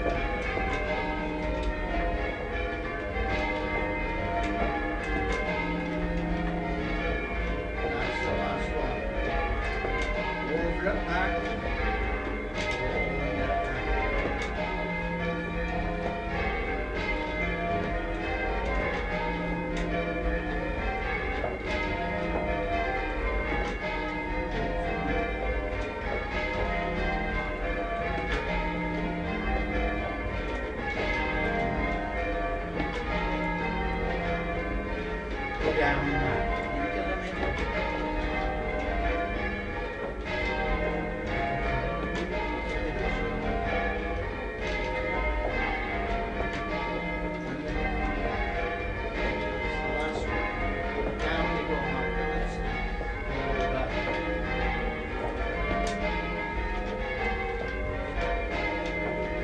Bells at St Stephens Church.